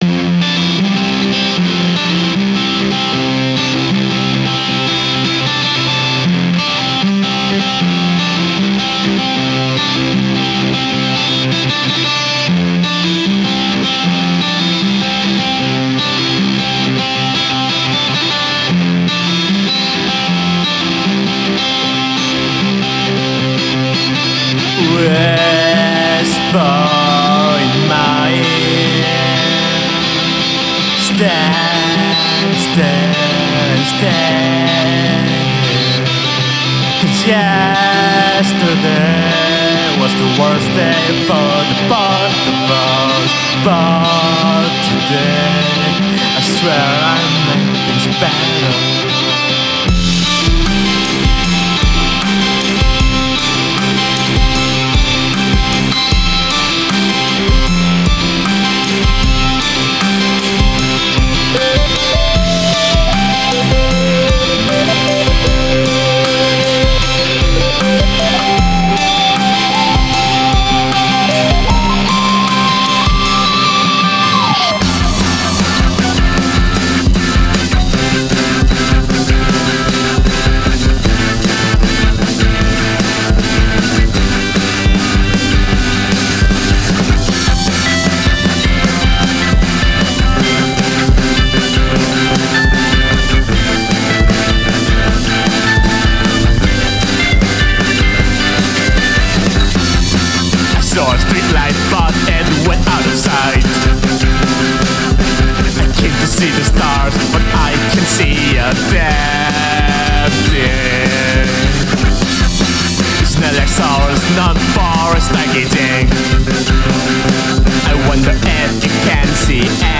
It's an emo album about being a sad bunny.
Guitar, bass, bg vocals
saxophone